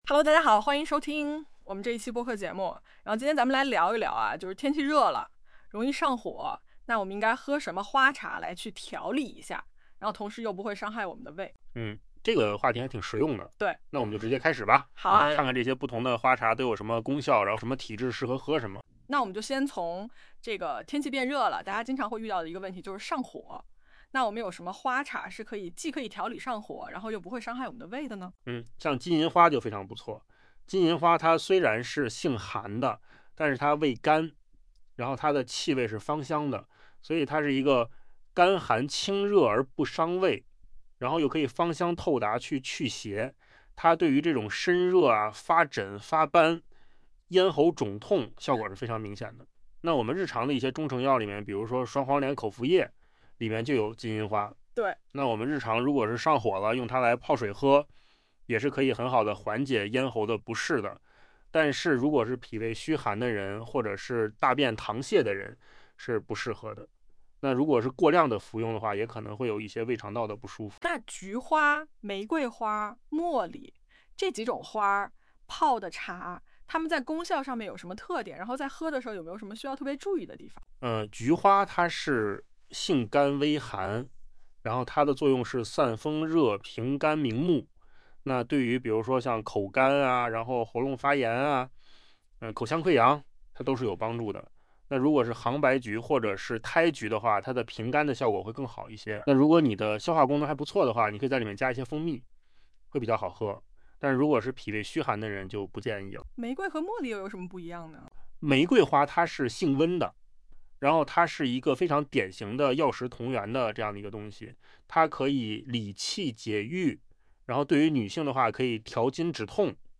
从生成的音频来看，这种“真人感”增加了我对这些养生知识的兴趣和信任度，这个任务对AI来说就很简单了。
不得不赞叹，扣子空间生成的播客和真人录制的语音效果几乎没有区别了，AI免费打工的图景正成为现实。